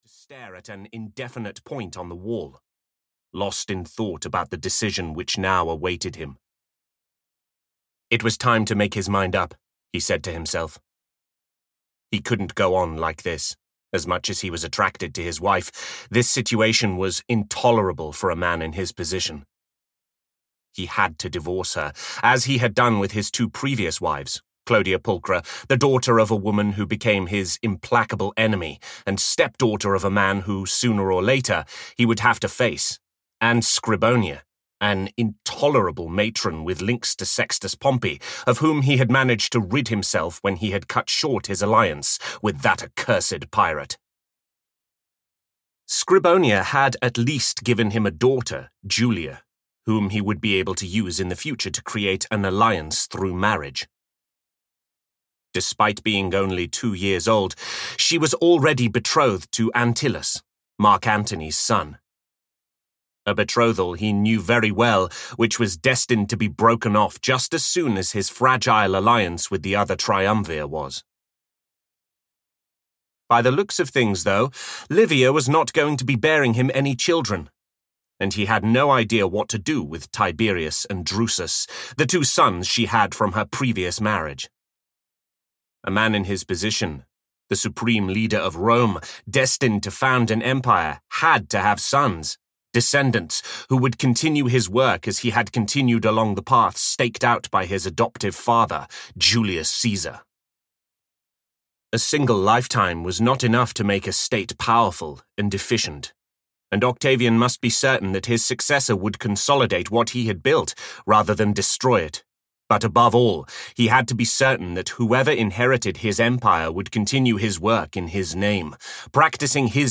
Conquest (EN) audiokniha
Ukázka z knihy